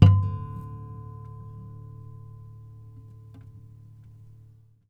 harmonic-11.wav